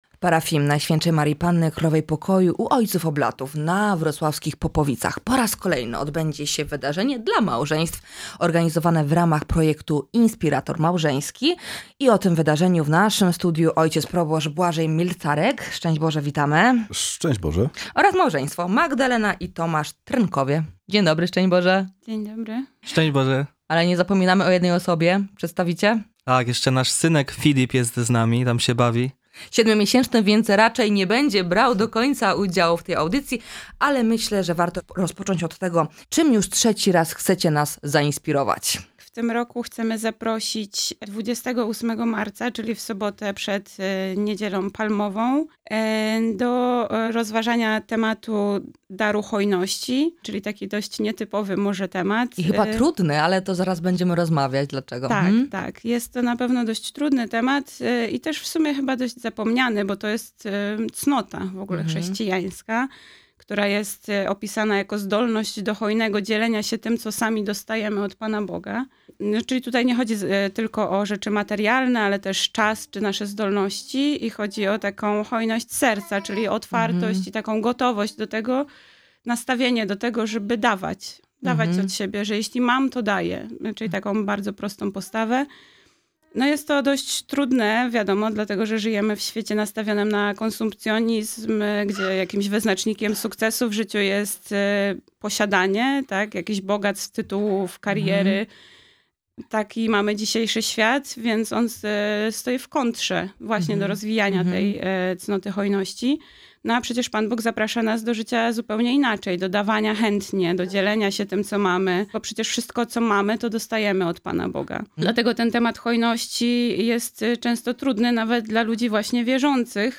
A w naszym studiu